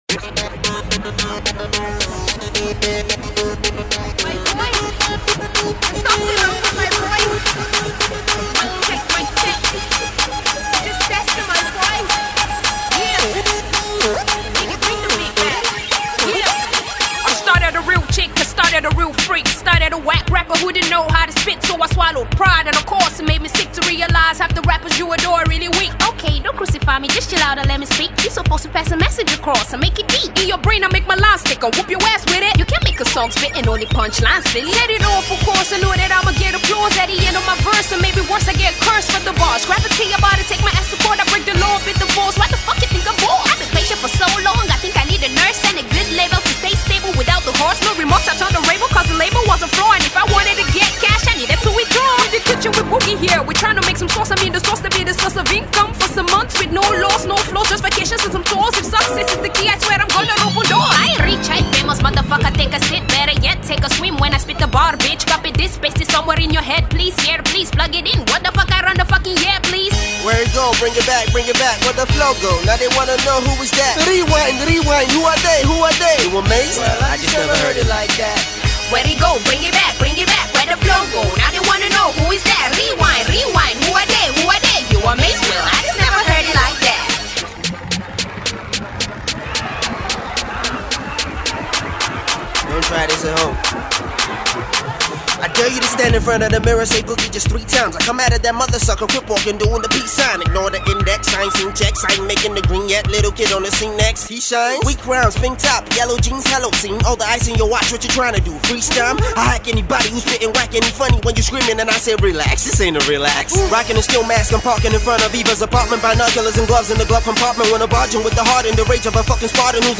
witty punchlines and impeccable flows
new school Naija Hip-Hop track